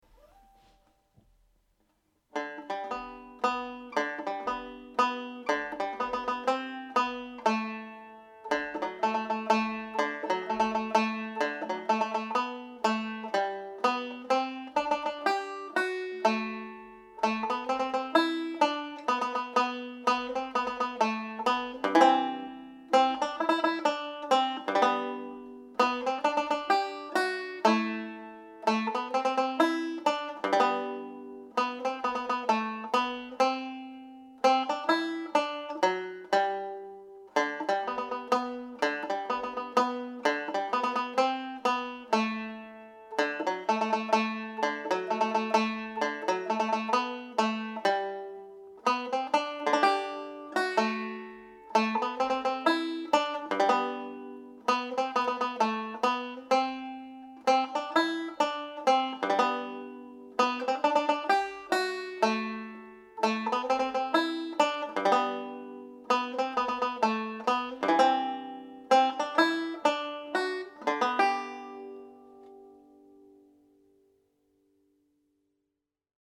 Shoe the Donkey played with triplets